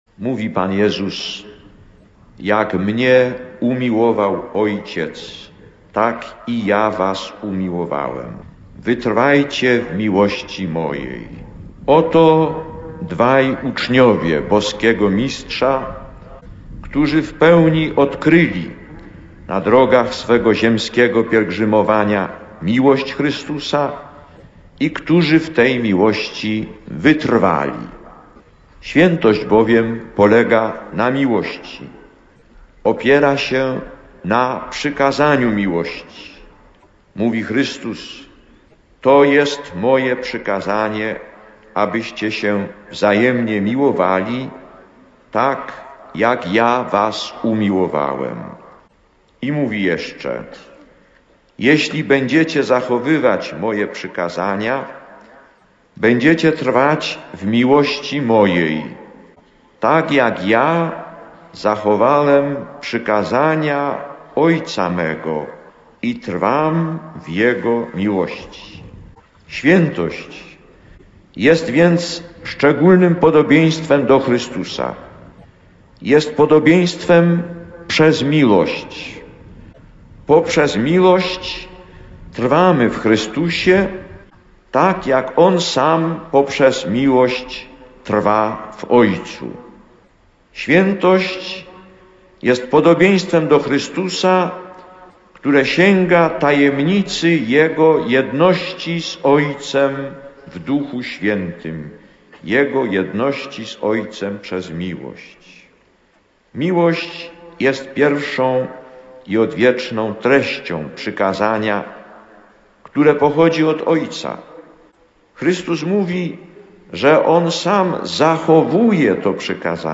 Lektor: Z homilii podczas Mszy św. beatyfikacyjnej o. Rafała Kalinowskiego i Brata Alberta Adama Chmielowskiego (Kraków 22 czerwca 1983